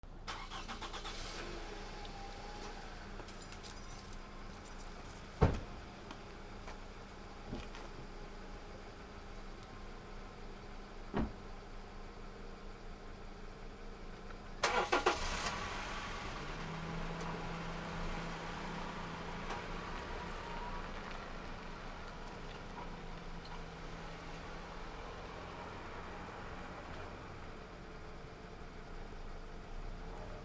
200 自動車 エンジン 足音あり
/ E｜乗り物 / E-10 ｜自動車